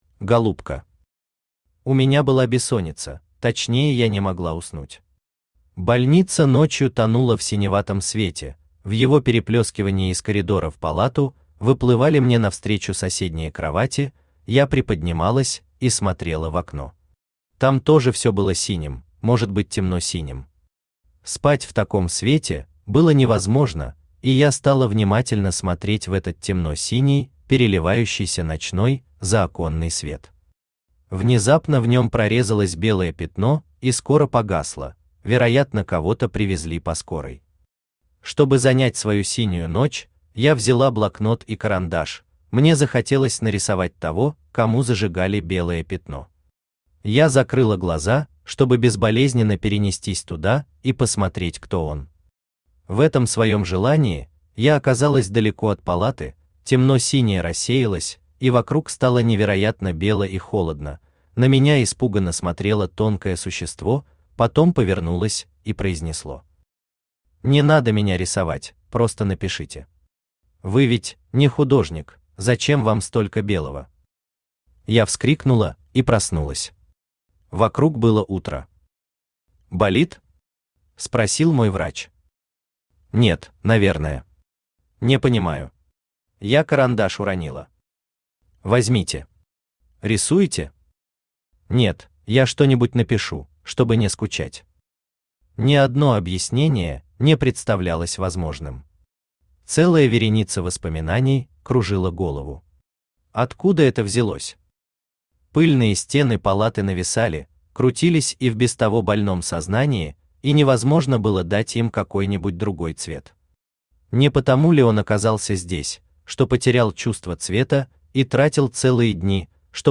Аудиокнига Голубка | Библиотека аудиокниг
Aудиокнига Голубка Автор Оксана Лисковая Читает аудиокнигу Авточтец ЛитРес.